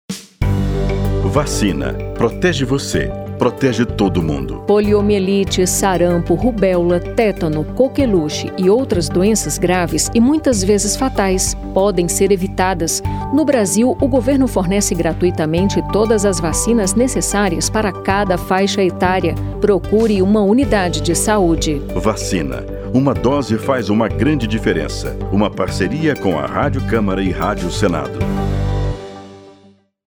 09-spot-doenas-com-vacina-camara-com-senado.mp3